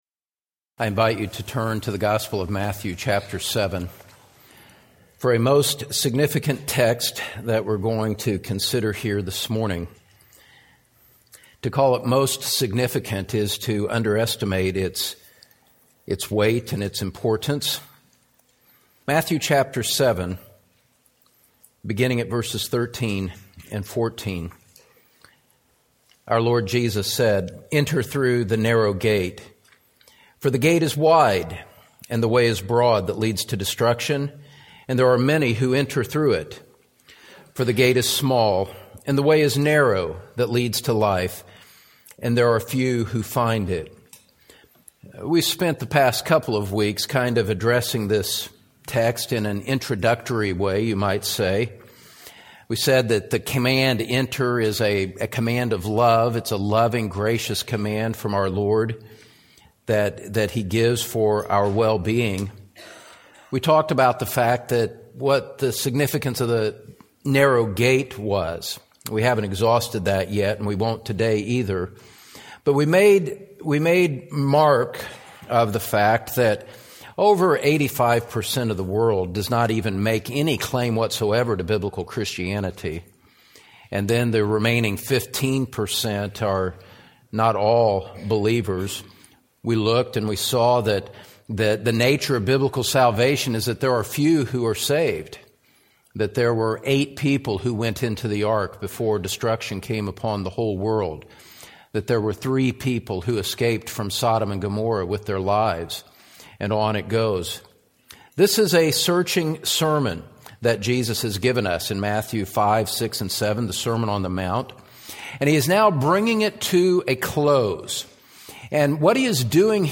The Broad Way to Hell | SermonAudio Broadcaster is Live View the Live Stream Share this sermon Disabled by adblocker Copy URL Copied!